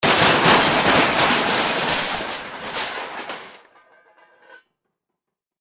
Звуки грохота, падений
Громкий грохот от падения тяжелого предмета с высоты